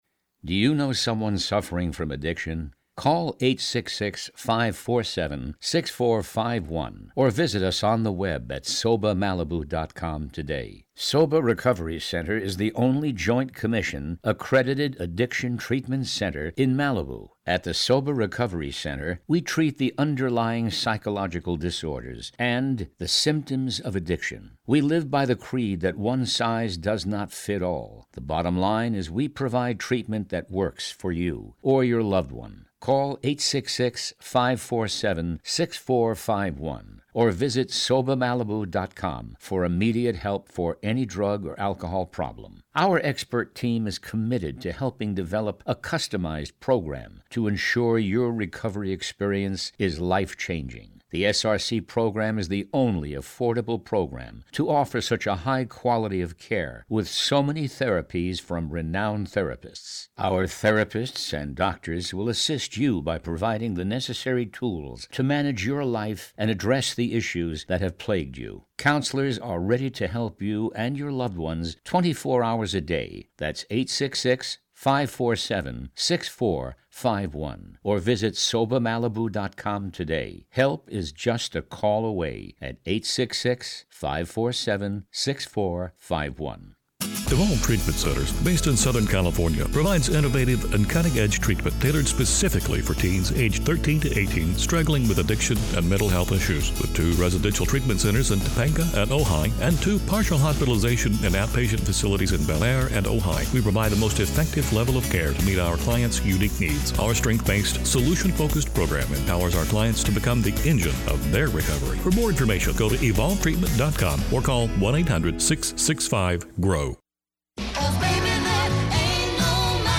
Finally, a radio show that not only tackles the brighter side of addiction, recovery, and mental health, but a show with the answers for the family, friends, and those who love an addict.